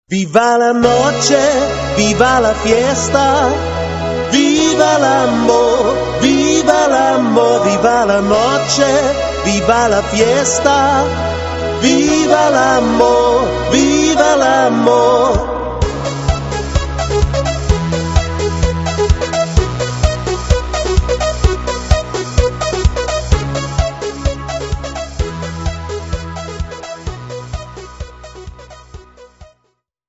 Party-Music-Band